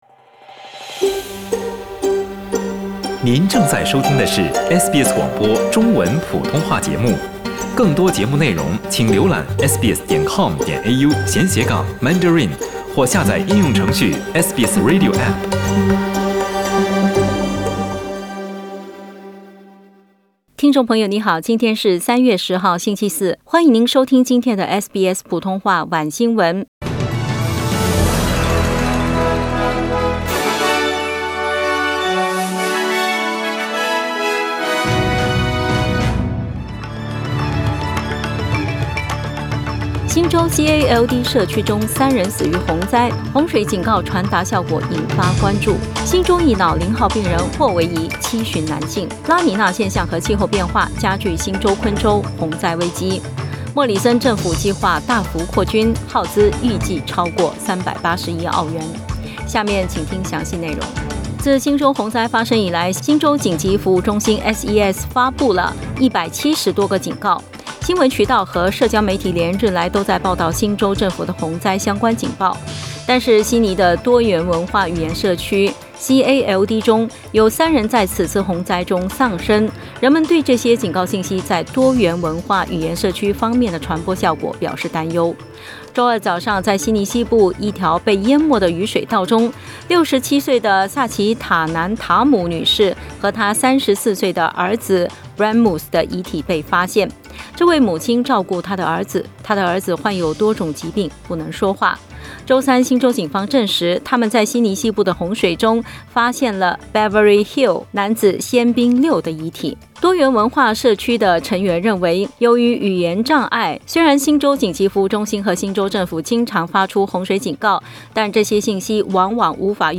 SBS晚新聞（2022年3月10日）
SBS Mandarin evening news Source: Getty Images